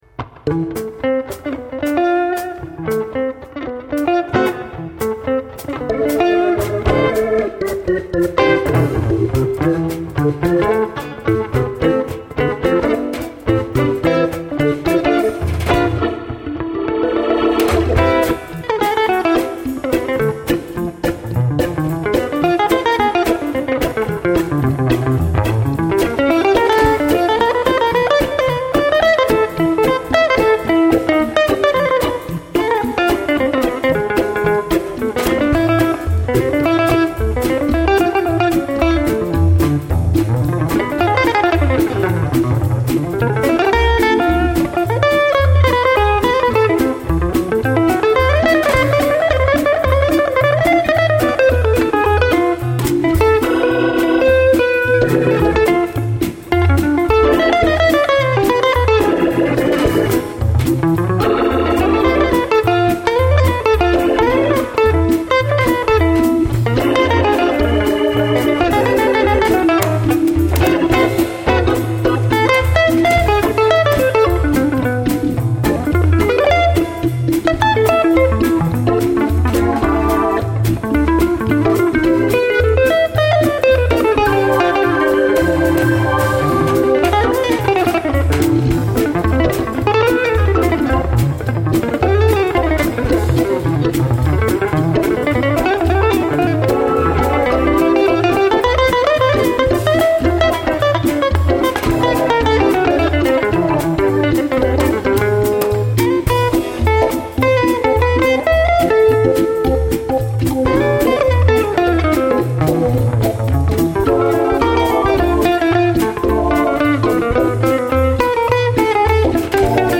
Un petit extrait enregistré en allemagne .
Guitare manouch ,xk1,drums.